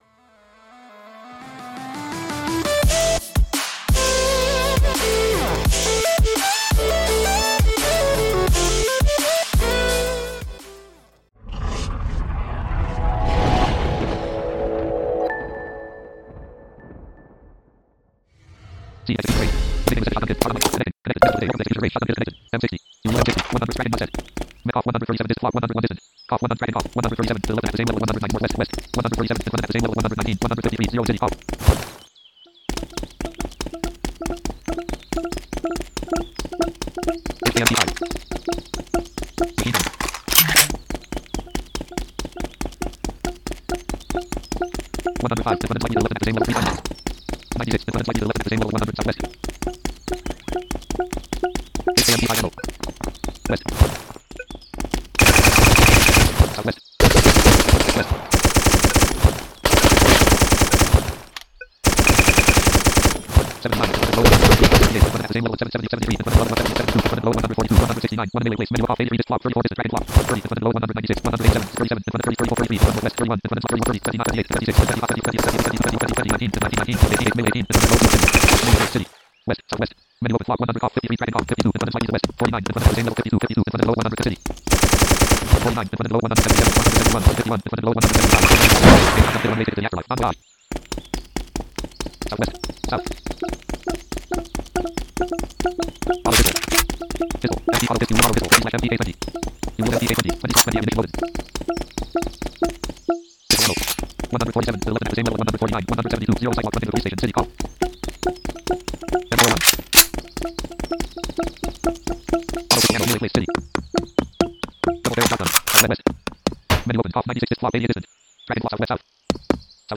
audio teaser